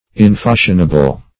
\In*fash"ion*a*ble\